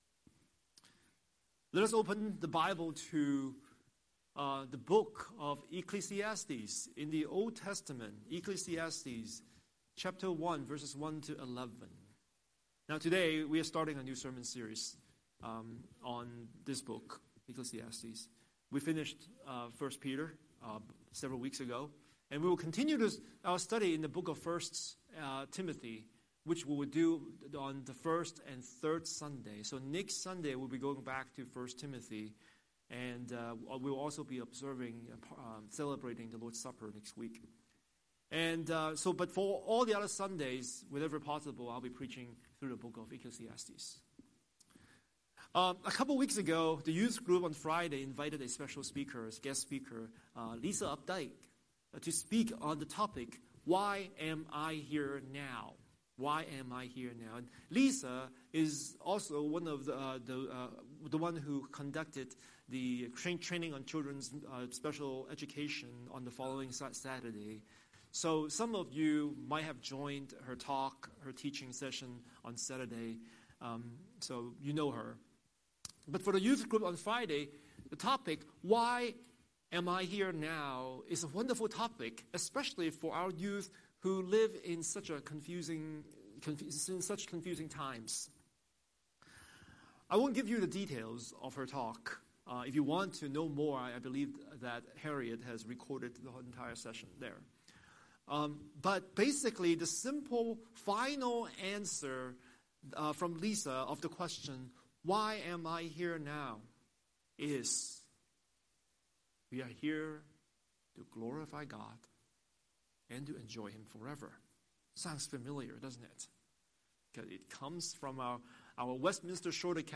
Scripture: Ecclesiastes 1:1–11 Series: Sunday Sermon